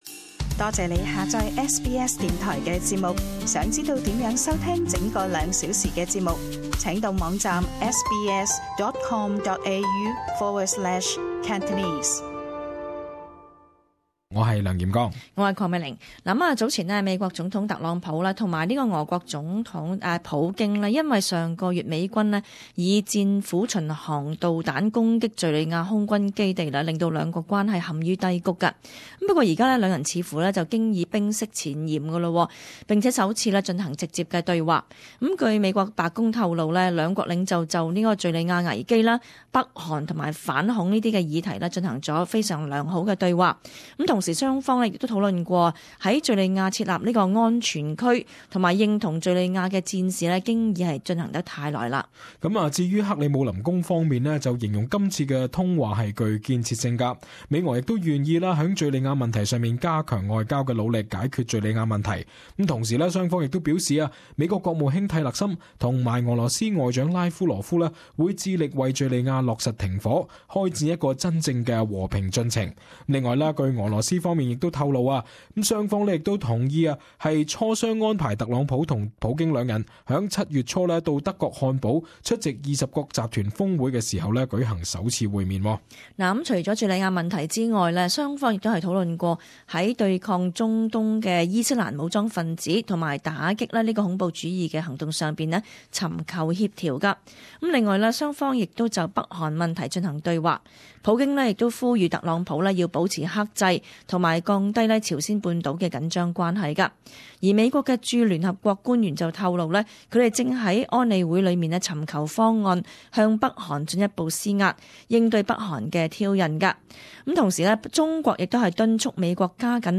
【時事報導】美俄兩國領袖自敘利亞空襲後首次對話